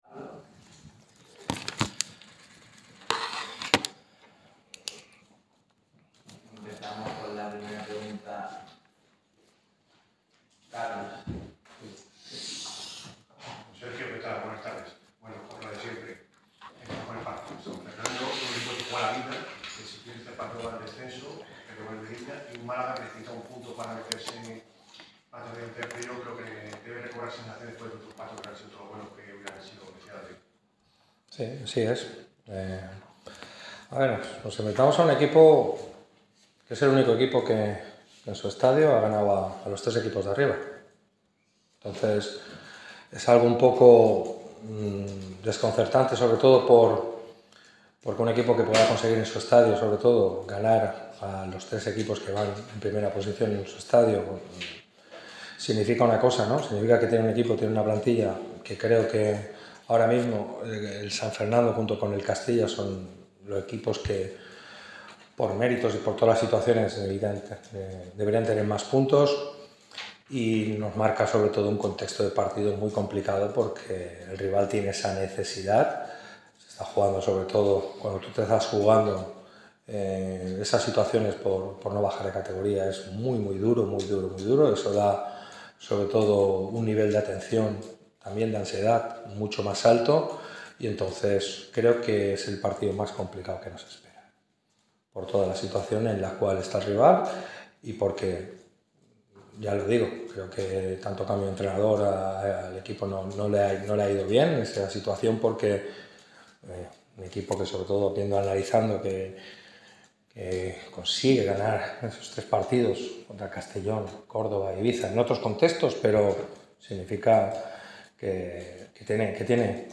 El técnico de Nules ha comparecido ante los medios en la previa del duelo que enfrentará a los boquerones contra el San Fernando en el Estadio Iberoamericano